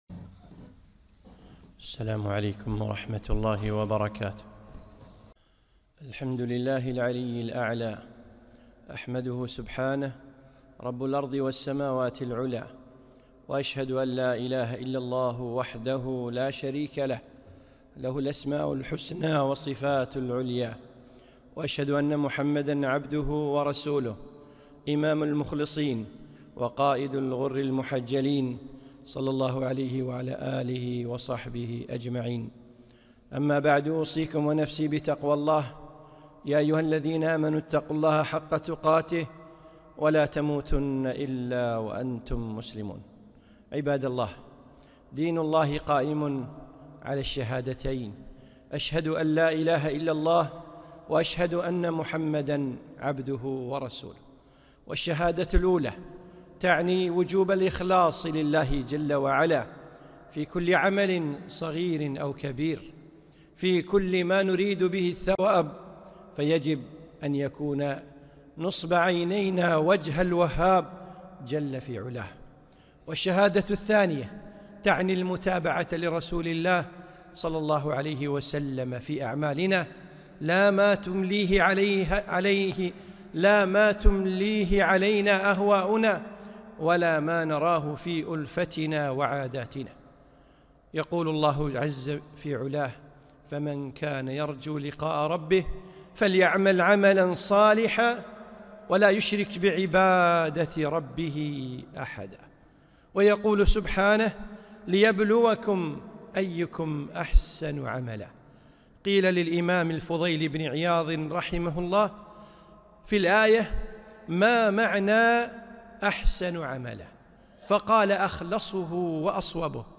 خطبة - احذر الرياء